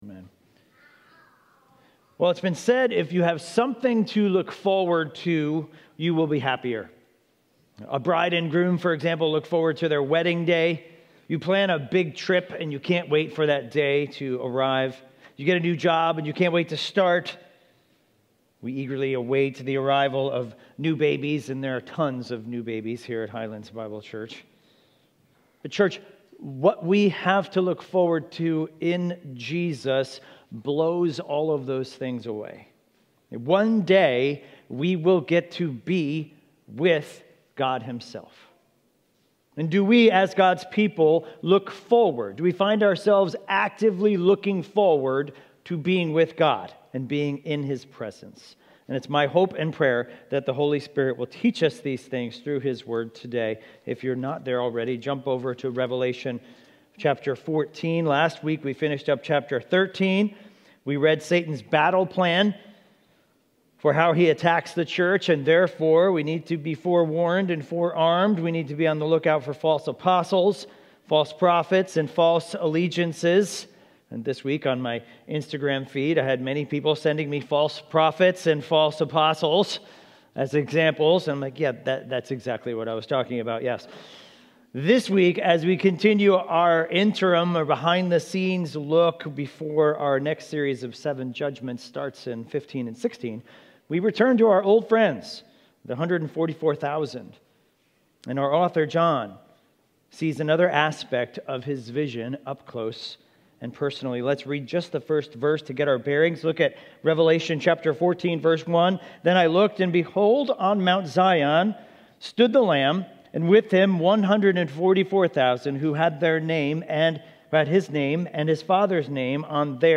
Highlands Bible Church Sermon Audio